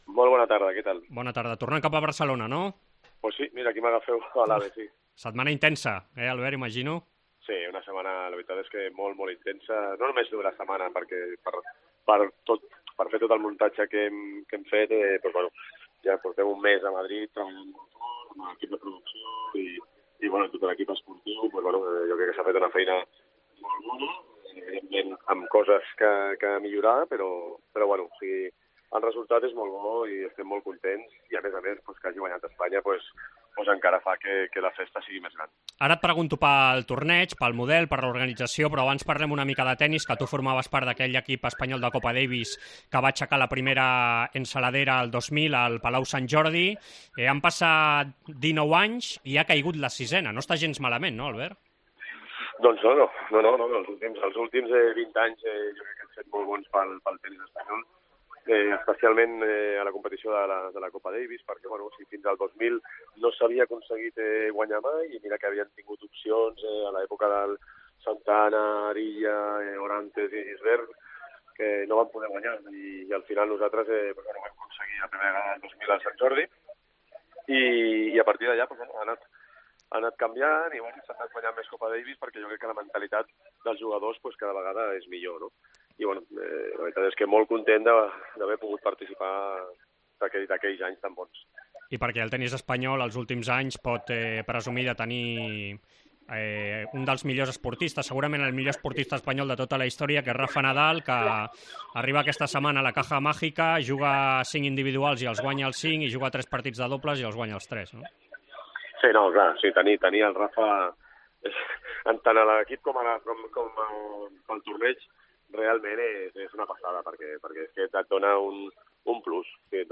AUDIO: Entrevista amb el Director de les Finals de Copa Davis, Albert Costa